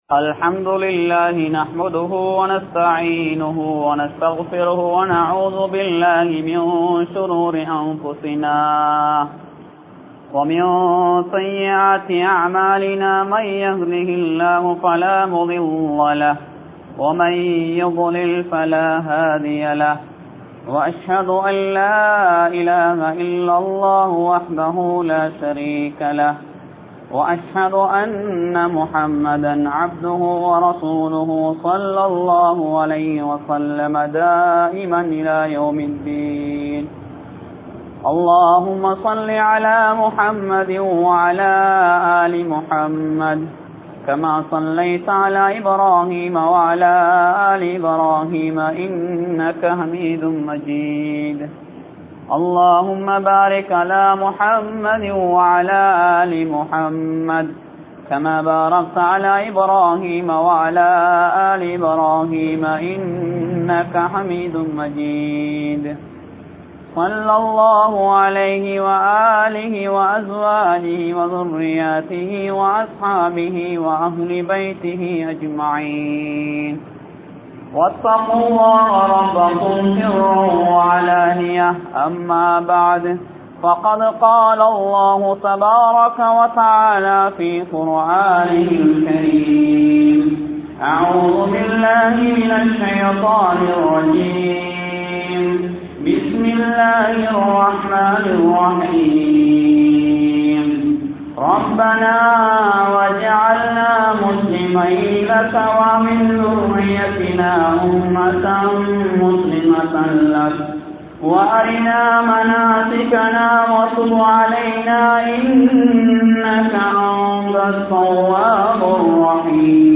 Dua Keatpathan Olukkangal (துஆ கேட்பதன் ஒழுக்கங்கள்) | Audio Bayans | All Ceylon Muslim Youth Community | Addalaichenai
Kaduwela, Weliwita Araliya Mawatta Jumua Masjidh